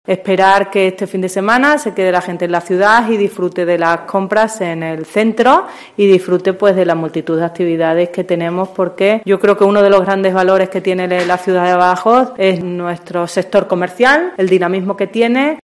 Blanca Subirán, concejala de comercio, presenta VIVE LA PRIMAVERA